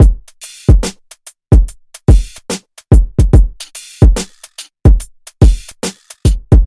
标签： 94 bpm Boom Bap Loops Drum Loops 3.44 MB wav Key : Unknown FL Studio
声道立体声